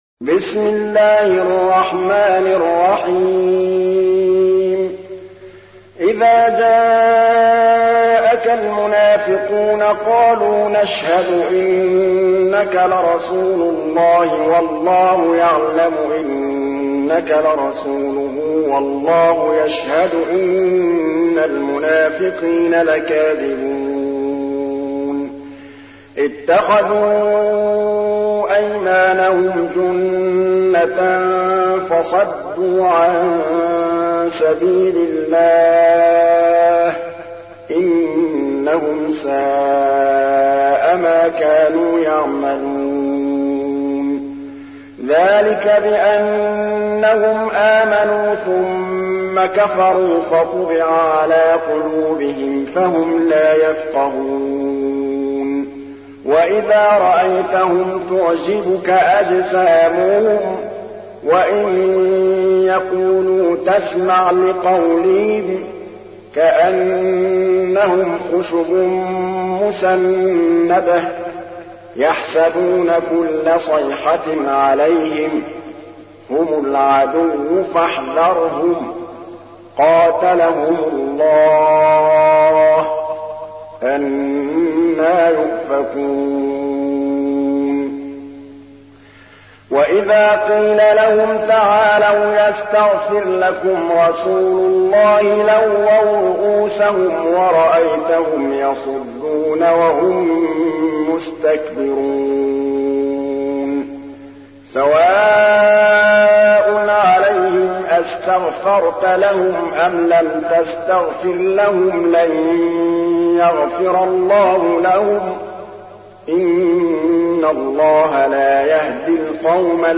تحميل : 63. سورة المنافقون / القارئ محمود الطبلاوي / القرآن الكريم / موقع يا حسين